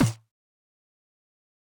Snares / Lock Snare